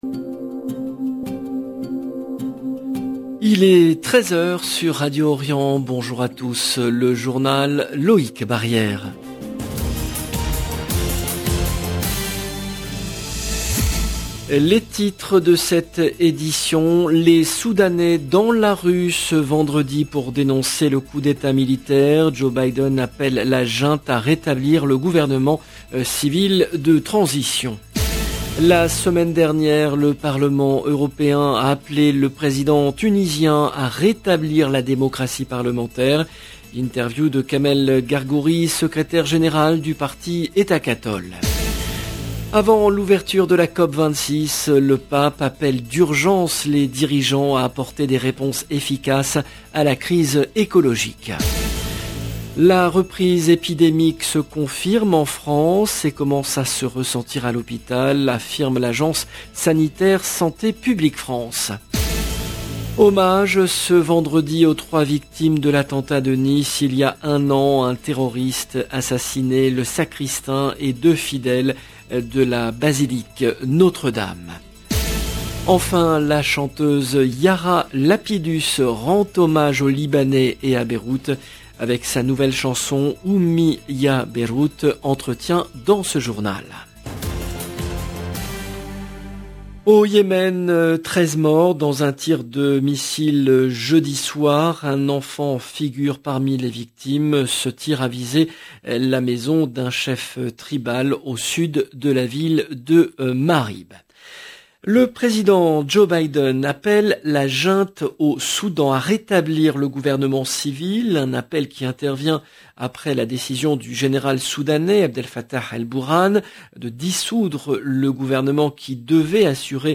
Entretien dans ce journal.